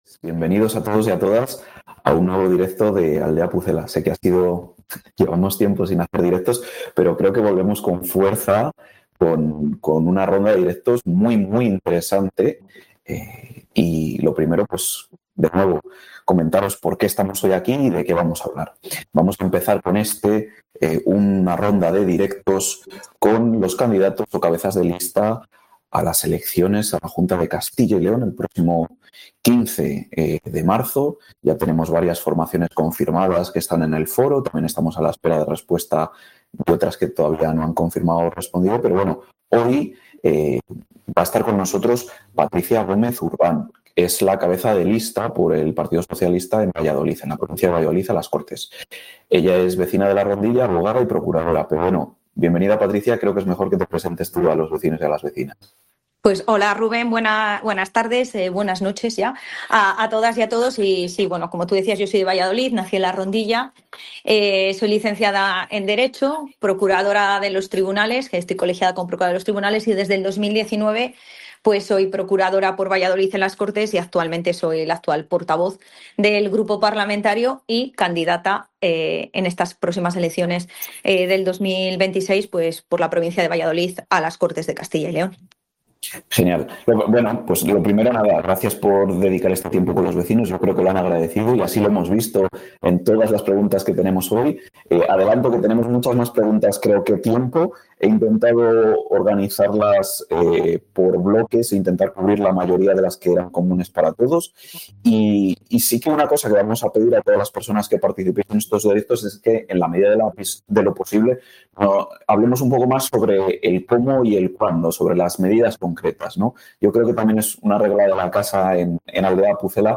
Iniciamos nuestros Directos con los candidatos a las elecciones de la Junta 2026.